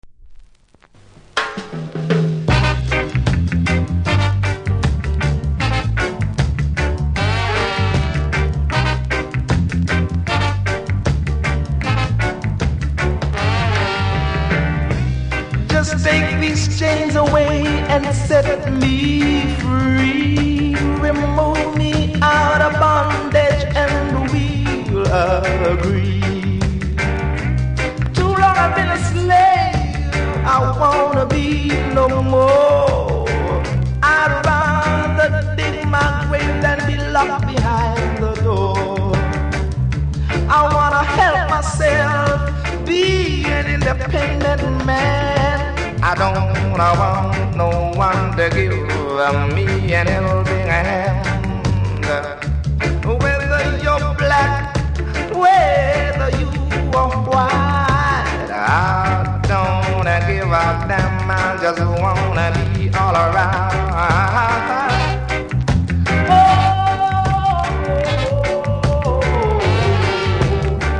キズ多めですが音にはそれほど影響されておりませんので試聴で確認下さい。